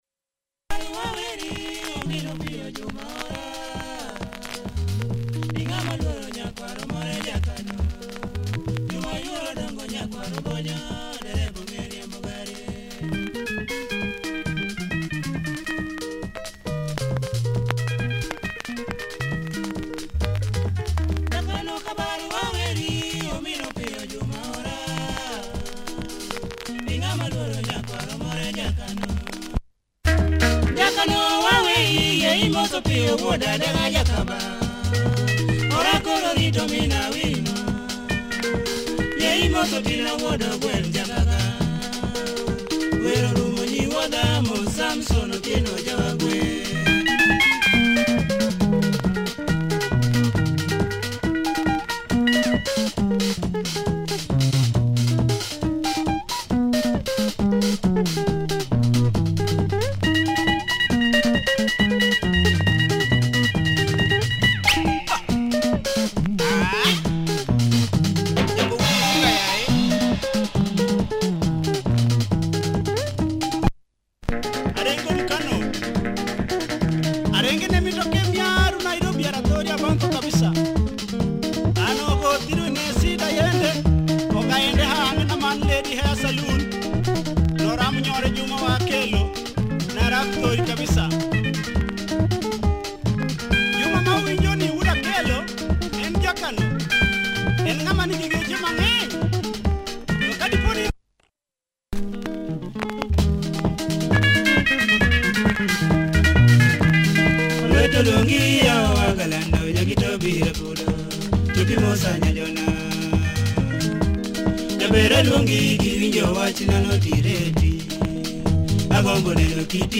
LUO benga